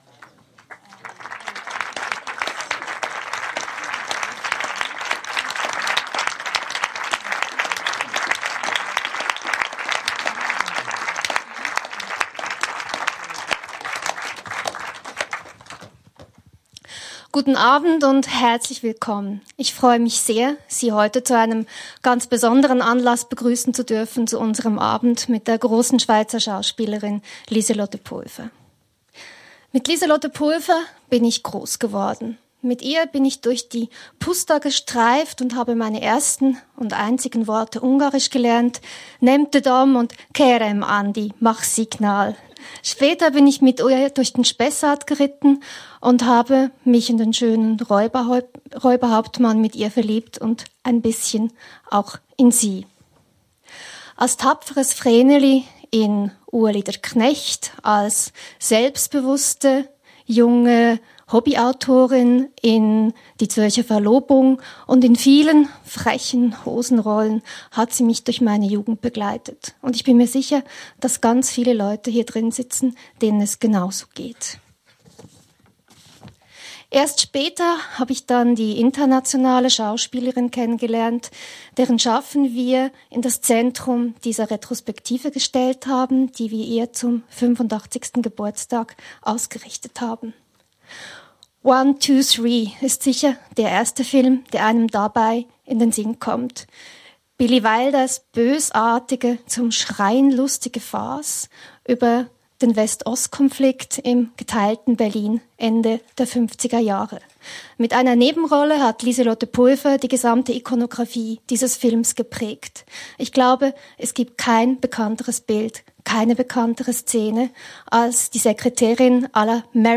Zu hören ist die Aufregung wohl nicht, meine Begeisterung aber bestimmt.